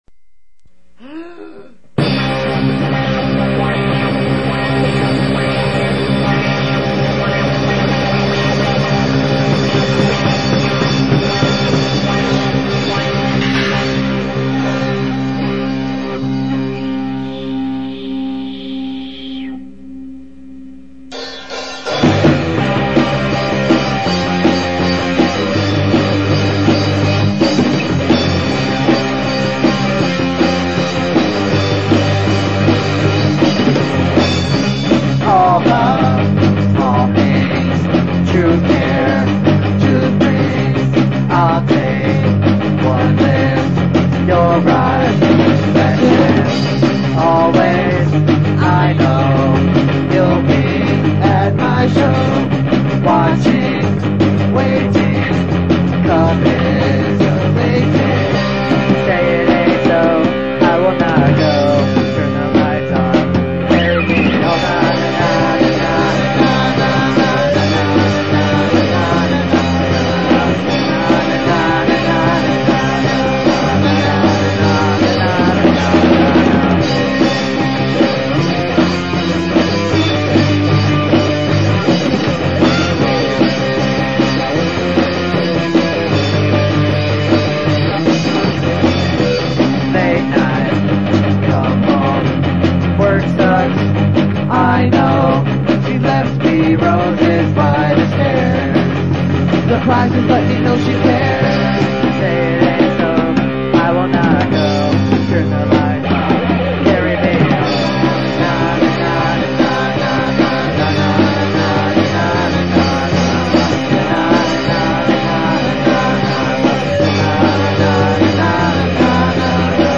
Guitar
Drums/Back-up Vocals
Bass/Vocals